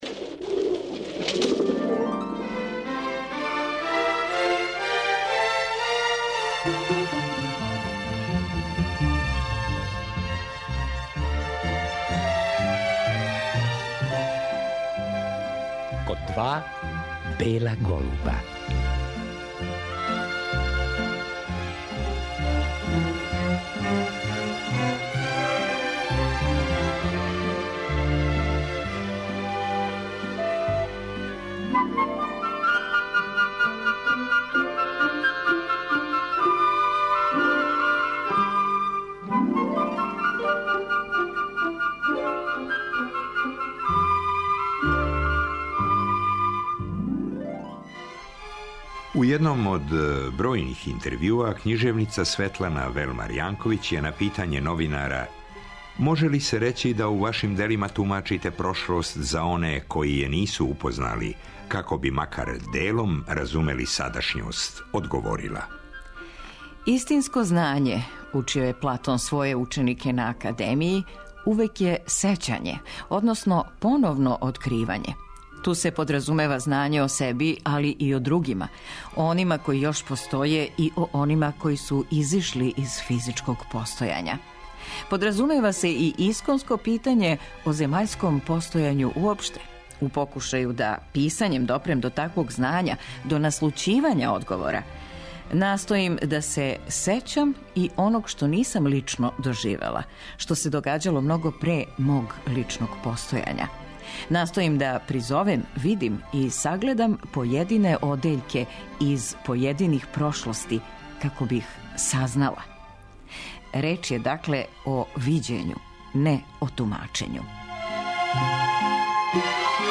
Светлана је била драги гост ове емисије.